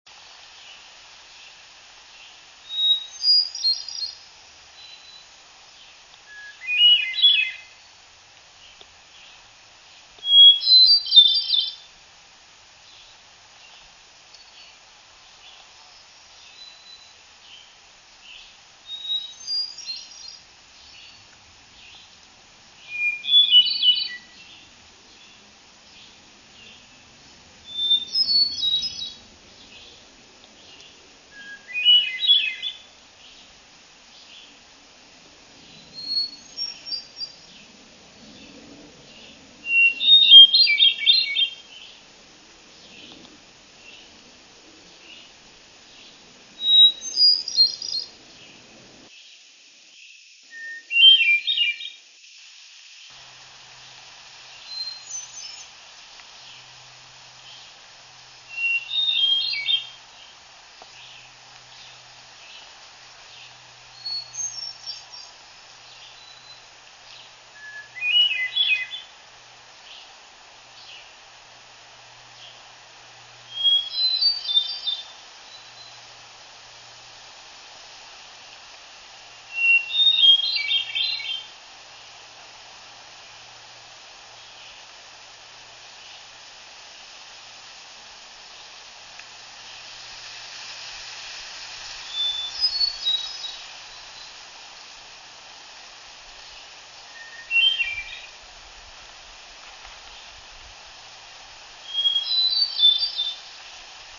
Hermit Thrush
Eastern Hermit Thrush on breeding territory, Slide Mountain, Catskills Preserve, Ulster County, New York, 6/23/04, 11:00 a.m. (406kb)  This bird was perched on a thick branch of a very mature Maple overhanging the trail.  Swainson's Thrush and Black-throated Green Warblers were also singing in the area.
thrush_hermit_837.wav